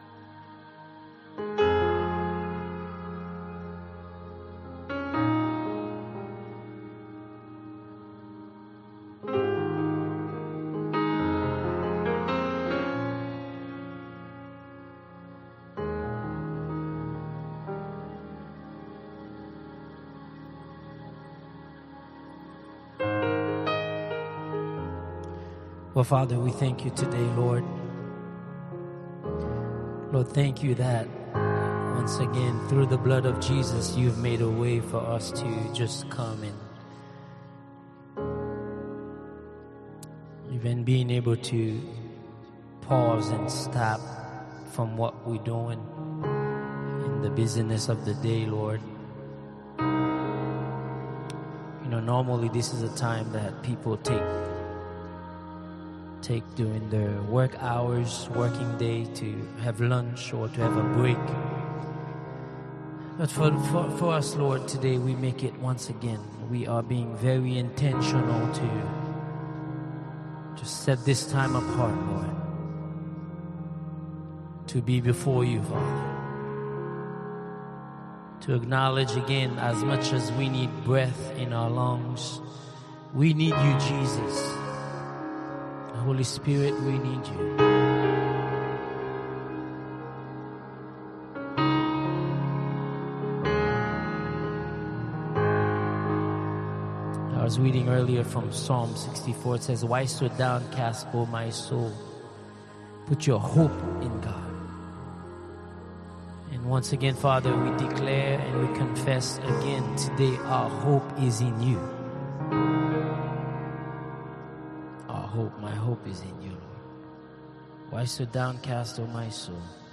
Soaking Prayer and Worship February 10, 2026 audio only